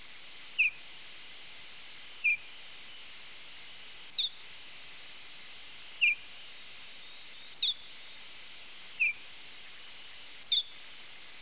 ORTOLANSPARV Emberiza horulana
Sträcker nattetid och kan då i stilla, gärna något disiga nätter ljuda högt uppifrån luften ett kort behagligt zie ofta följt efter några sekunder av ett mycket kort ty. Detta upprepas 2-3 gånger medan ortolansparven är inom hörhåll.
ortolan.wav